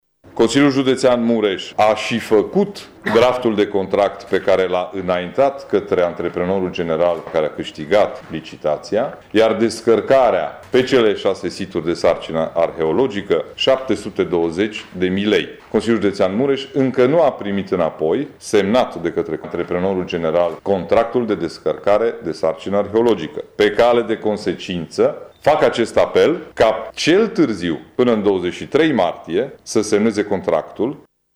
Președintele Consiliului Judetean Mureș, Ciprian Dobre, a precizat, astăzi, într-o conferință de presă, că valoarea celor doua investiții este de aproape 1,8 miliarde de euro.